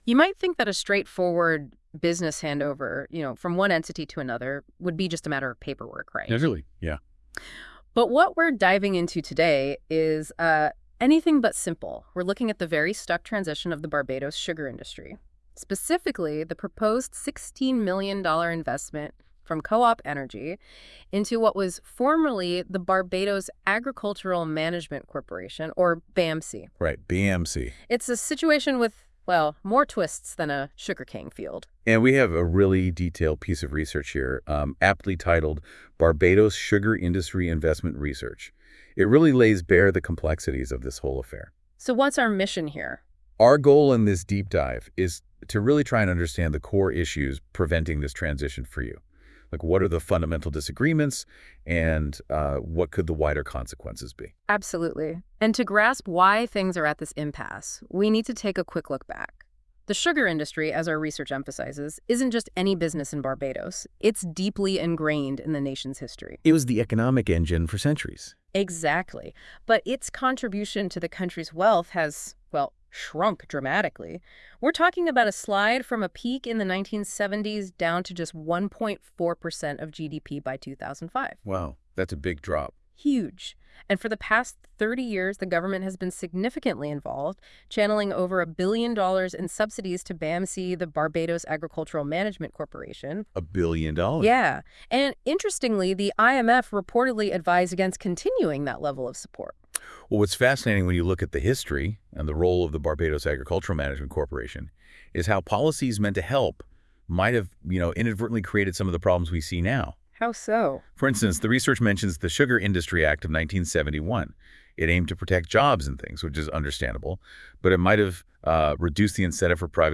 Expert commentary on the stalled transition of Barbados' sugar industry